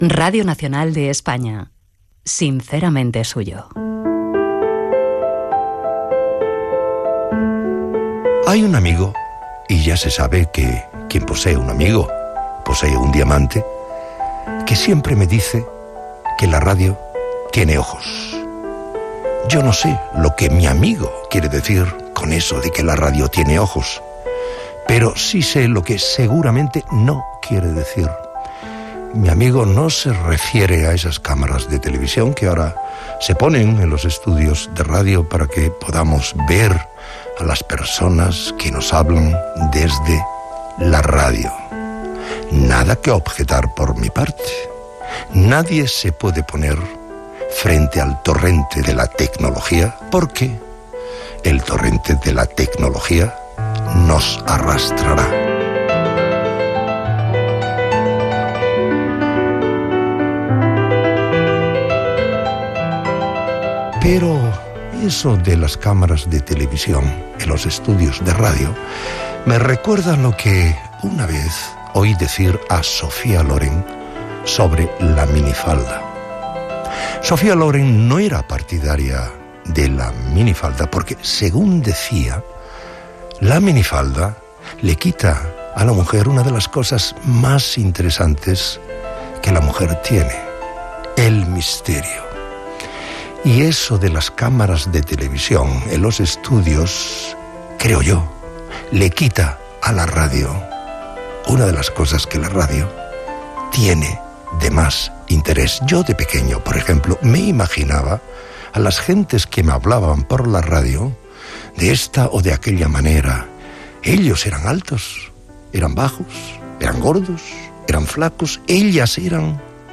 Careta del programa, presentació sobre el fet que la ràdio té "ulls" i tema musical
Musical